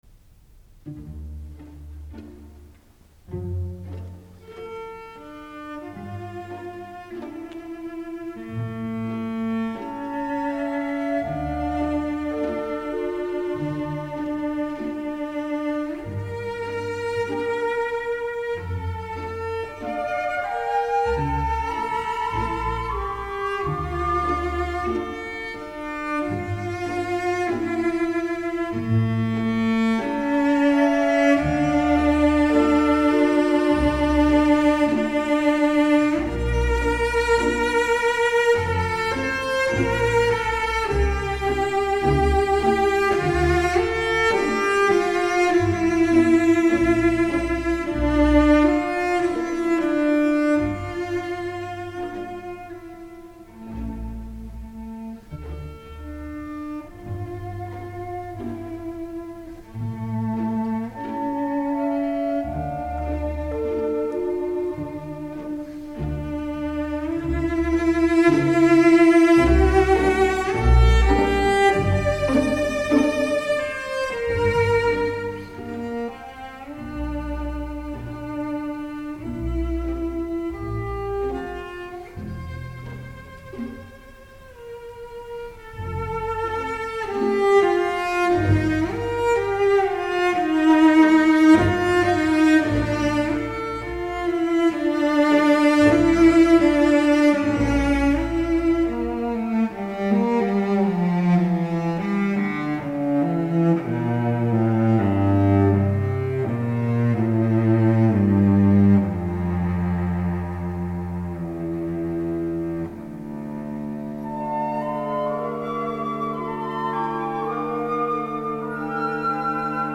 ДЛЯ ВИОЛОНЧЕЛИ С ОРКЕСТРОМ